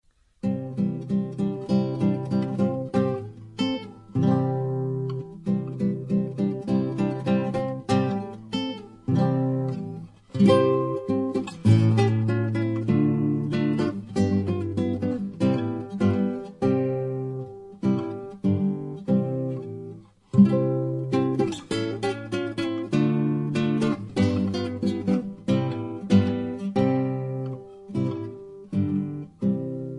Traditional Polish Christmas Carols on classical guitar
(No Singing).